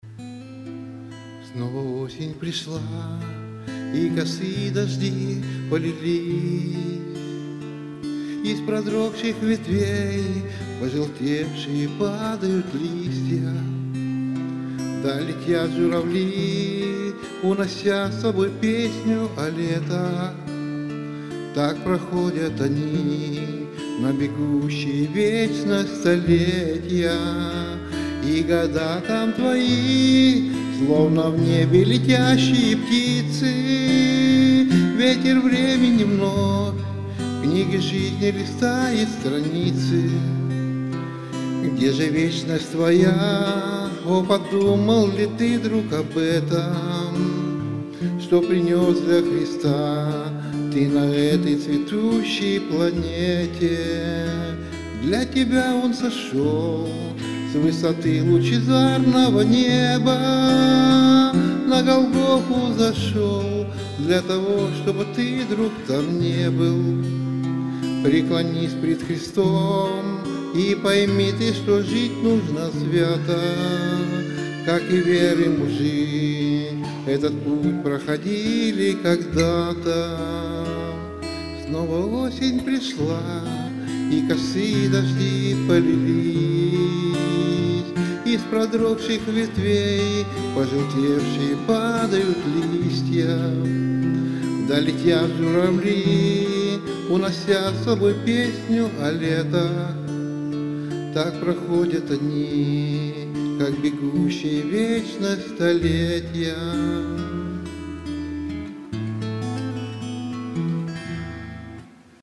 Богослужение 06.10.2024
Пение